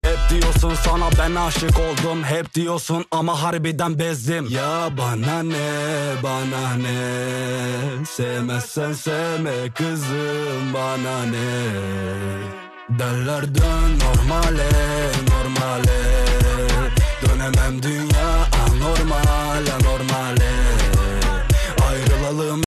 Audi A3 1.4t catback system sound effects free download
Audi A3 1.4t catback system custom egzoz uygulama